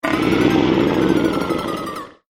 furious_03.ogg